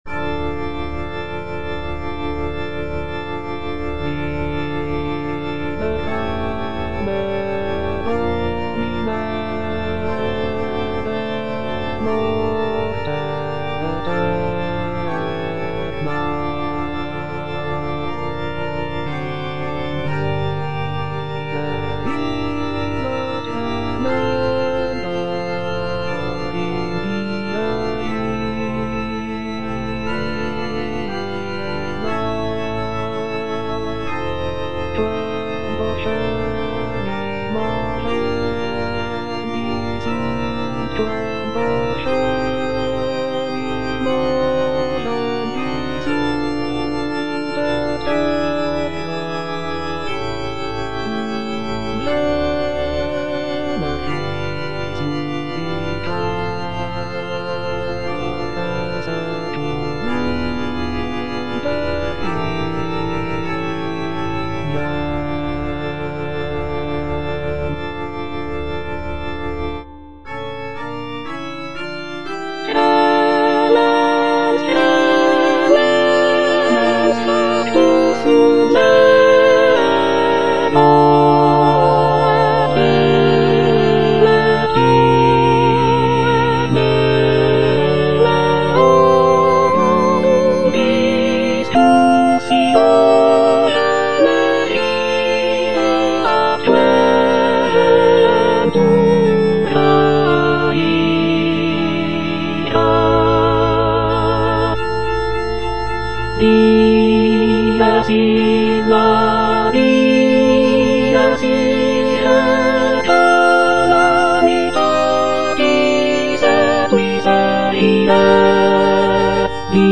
version with a smaller orchestra
Soprano (Emphasised voice and other voices) Ads stop